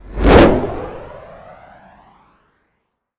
sci-fi_vehicle_pass_02.wav